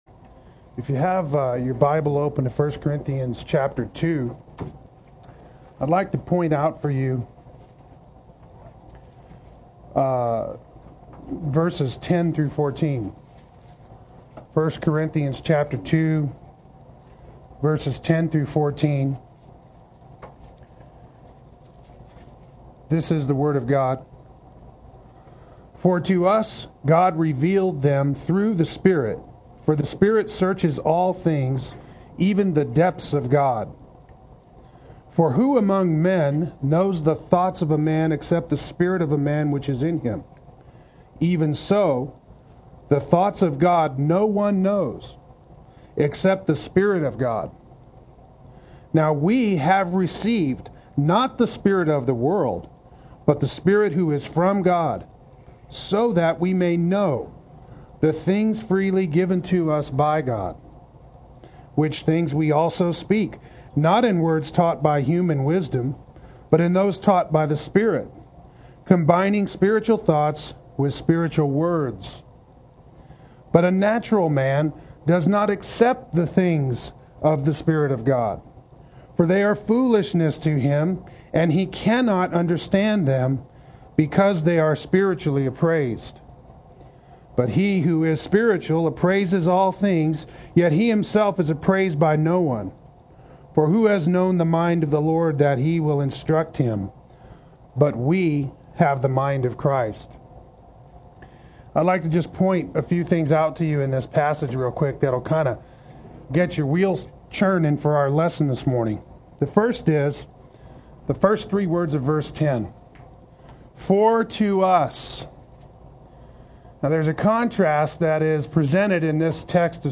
Play Sermon Get HCF Teaching Automatically.
Brought Forth by the Word of Truth Adult Sunday School